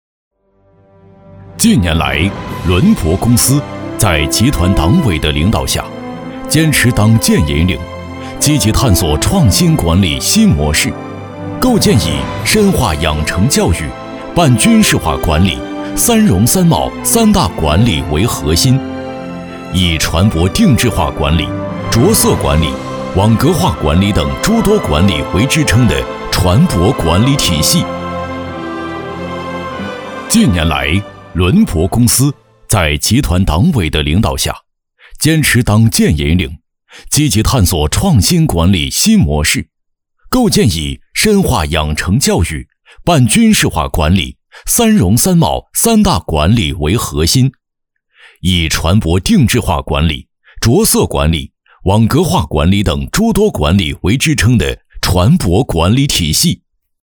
男13号